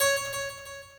harp4.ogg